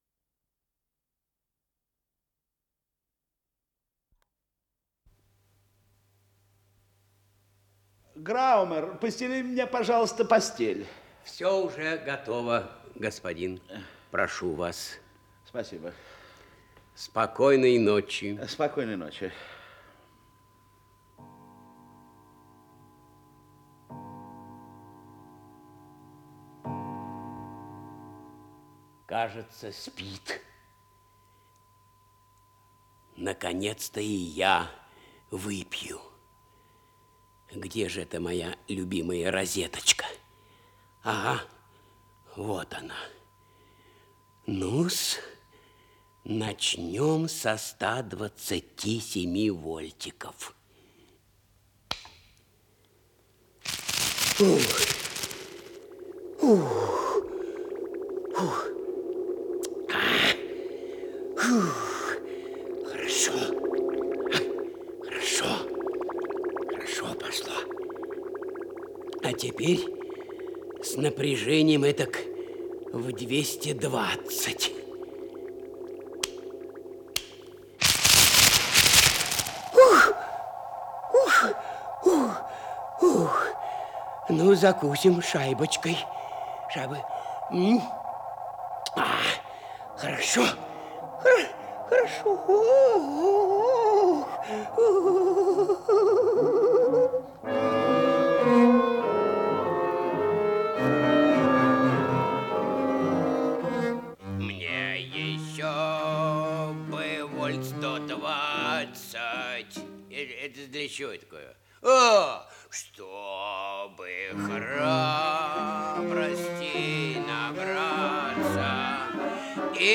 Исполнитель: Артисты московских театров
Подзаголовок Радиоспектакль